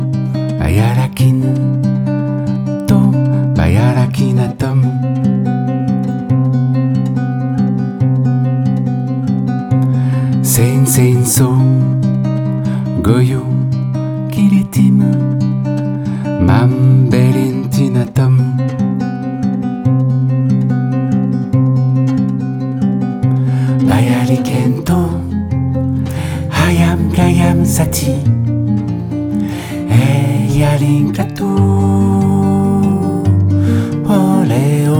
Жанр: Альтернатива / Фолк-рок
# Alternative Folk